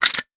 weapon_foley_pickup_22.wav